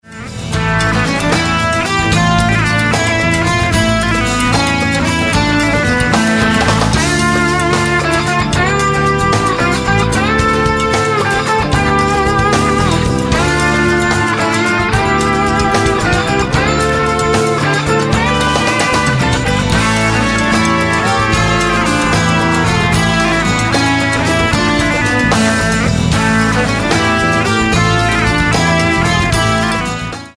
acoustic & electric guitars
drums
keyboards, lyricon, tenor saxophone
electric bass, vocals